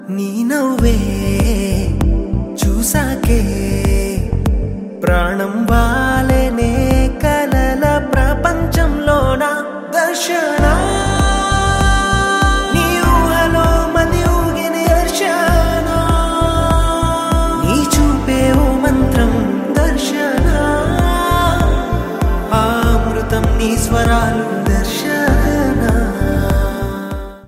is a gentle Telugu melody
with his soft and emotional voice